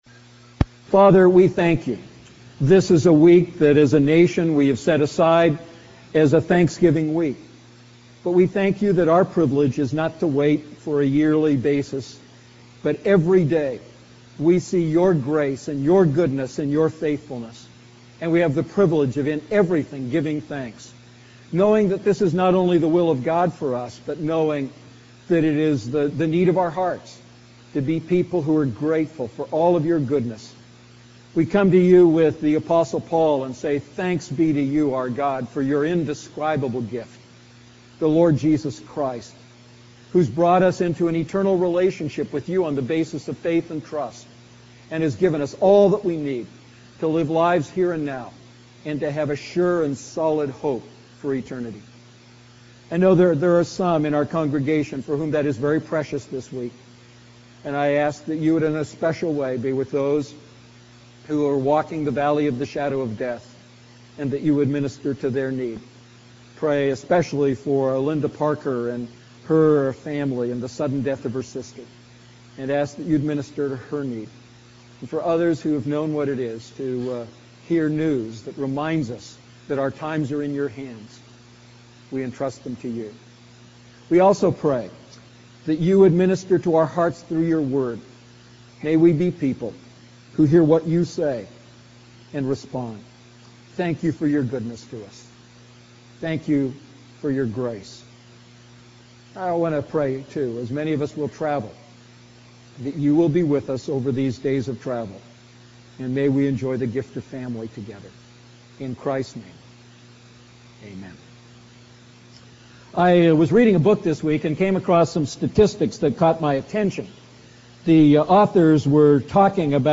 A message from the series "Prayer."